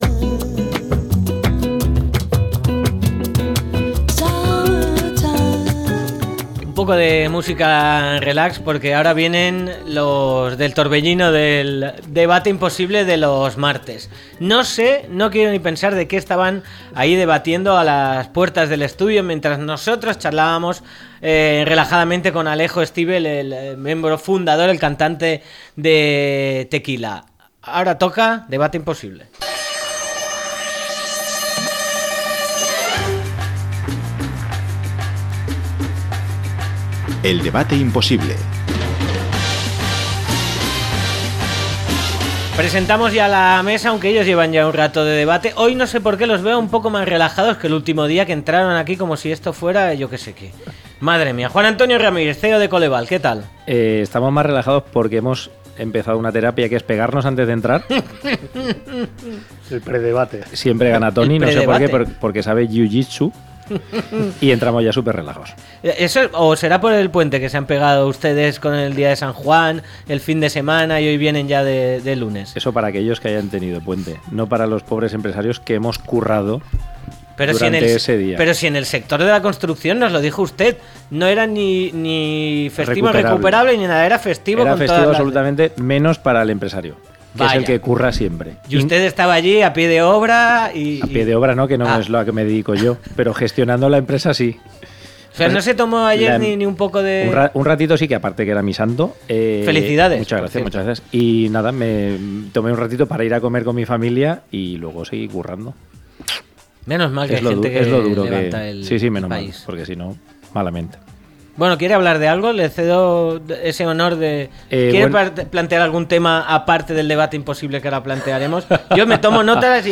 ¿Dónde ‘colocar’ a los hijos en verano?, a debate - La tarde con Marina